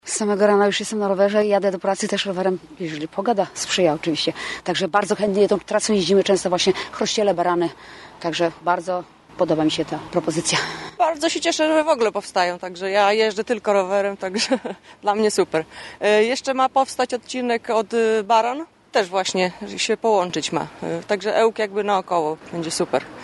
Cyklistki, z którymi rozmawialiśmy, chwalą takie inwestycje.
Rowerzystki.mp3